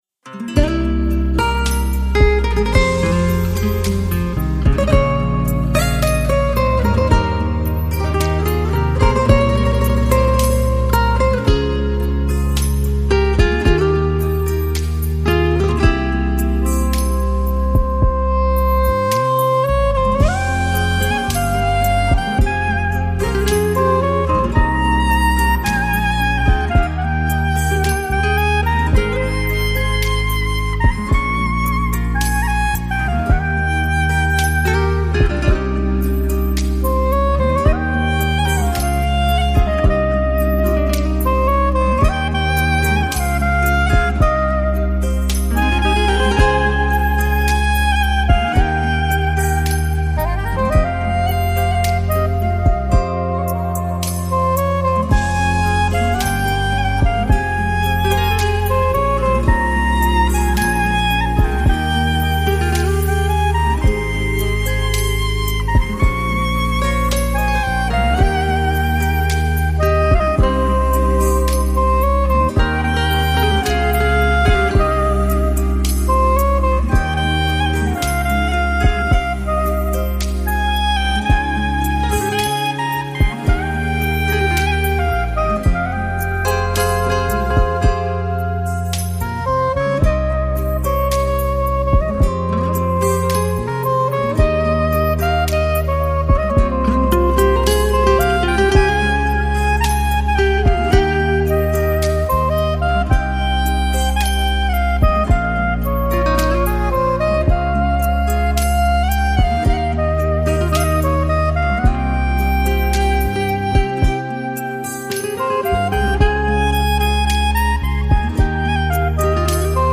در ژانر نیو ایج و به صورت بی‌کلام منتشر شد.
ساکسوفون سوپرانو
گیتار و گیتار باس